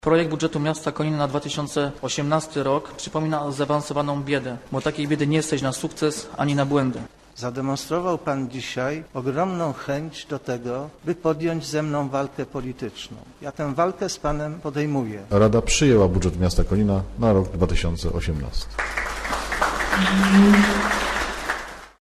Dyskusję na posiedzeniu rady miasta o budżecie Konina zdominowała utarczka słowna między przewodniczącym klubu radnych Prawa i Sprawiedliwości Karolem Skoczylasem a prezydentem Józefem Nowickim.